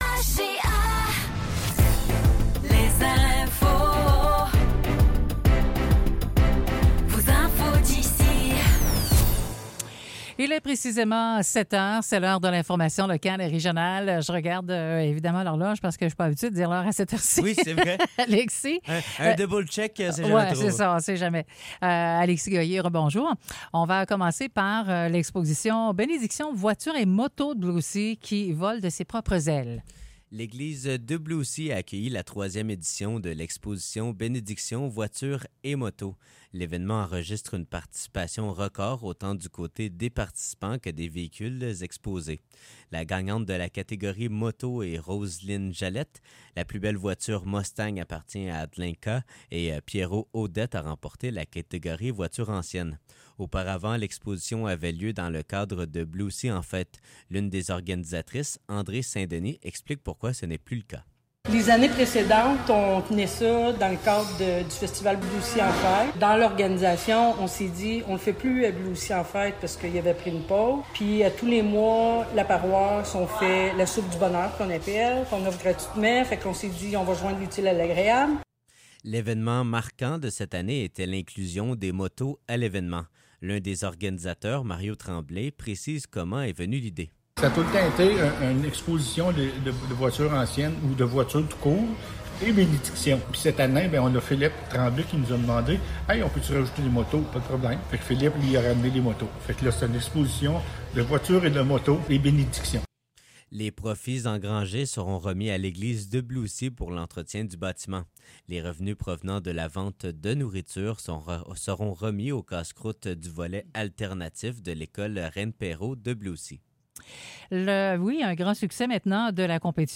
Nouvelles locales - 15 juillet 2024 - 7 h